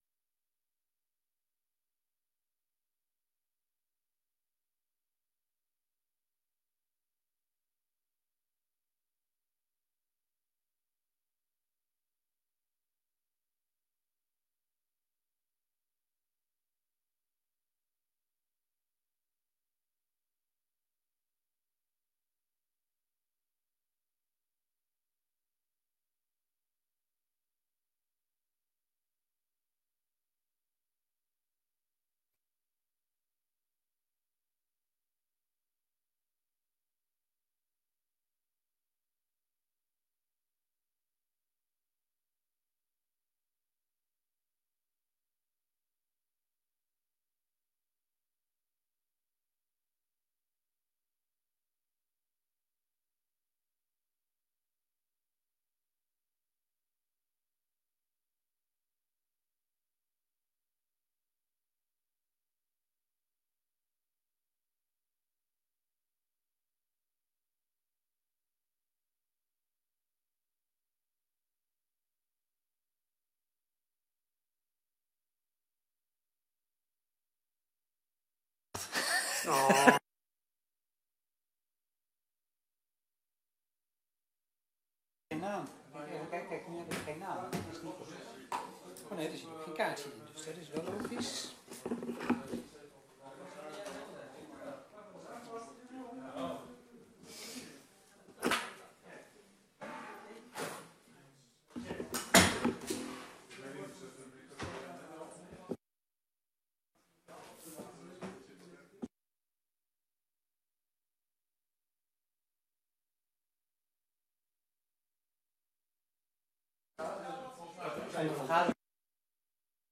Extra raadsbrede commissievergadering op dinsdag 27 september 2022, om 19:30 uur in de raadzaal van het gemeentehuis te Emmeloord.